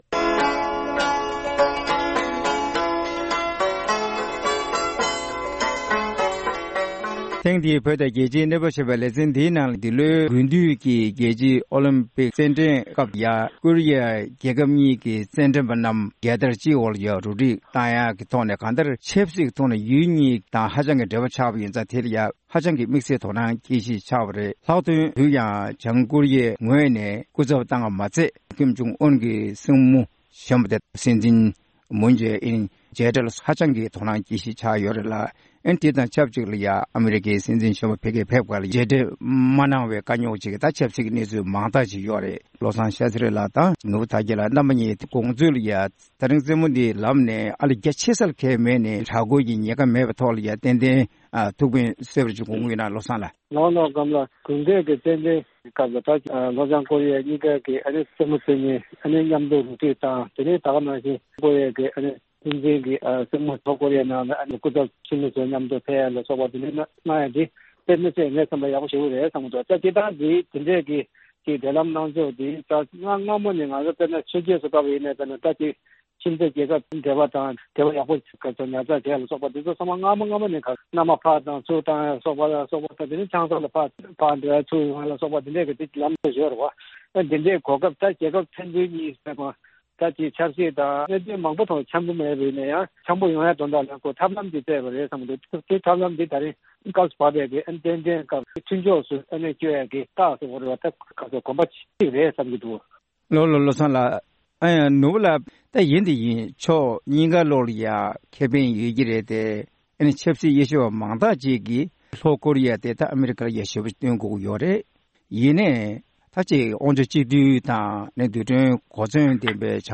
དགུན་དུས་ཀྱི་ཨོ་ལེམྤིཀྶེ་བརྒྱུད་ལྷོ་བྱང་ཀོ་རི་ཡ་གཉིས་ཟླ་སྒྲིལ་ཡོང་སྲིད་མིན་ཐད་གླེང་མོལ།